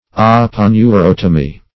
Search Result for " aponeurotomy" : The Collaborative International Dictionary of English v.0.48: Aponeurotomy \Ap`o*neu*rot"o*my\, n. [Aponeurosis + Gr.